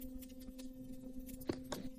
Golf Swing
Golf Swing is a free sfx sound effect available for download in MP3 format.
364_golf_swing.mp3